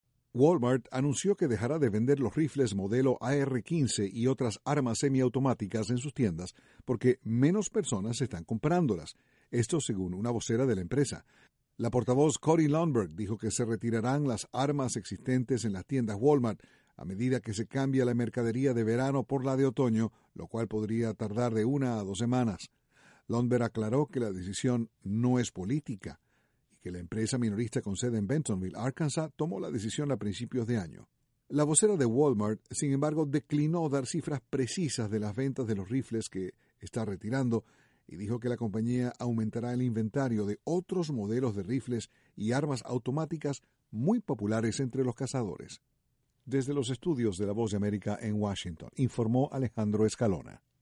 La tienda minorista Walmart anunció que dejará de vender ciertas armas semiautomáticas. Desde la Voz de América, Washington